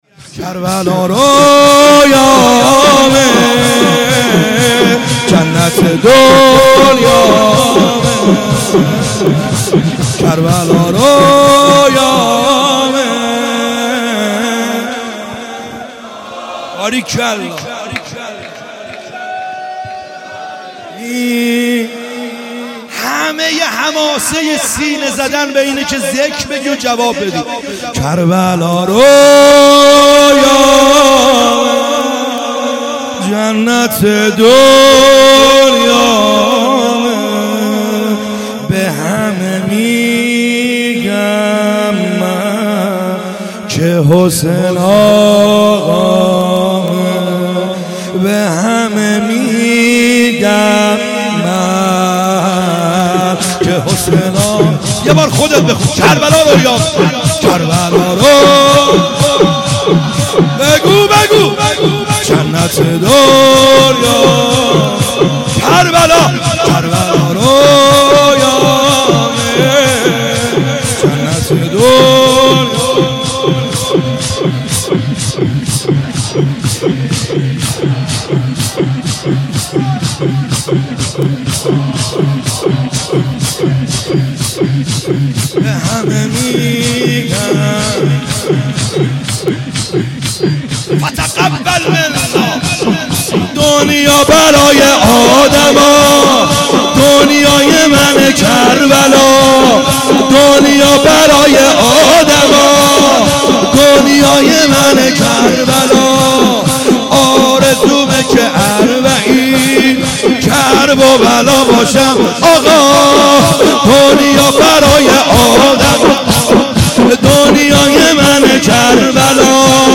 خیمه گاه - بیرق معظم محبین حضرت صاحب الزمان(عج) - شور | کربلا رویامه جنت دنیامه